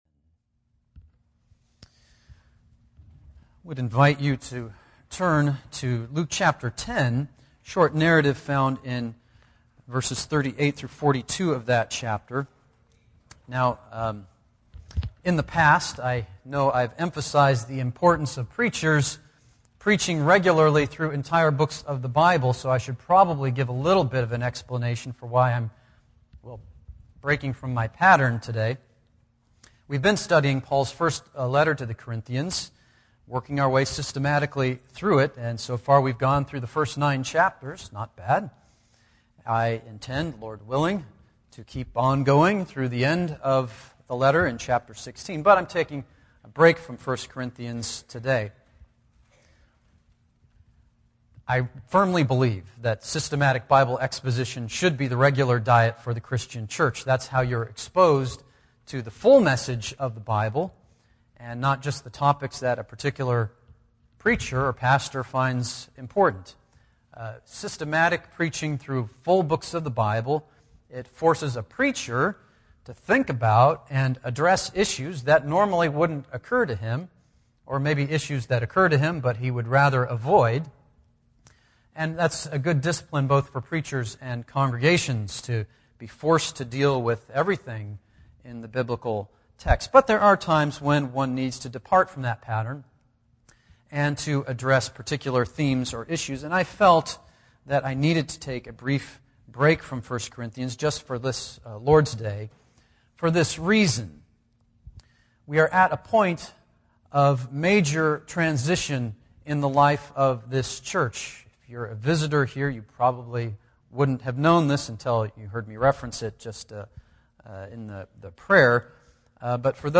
Podcast (sermon): Play in new window | Download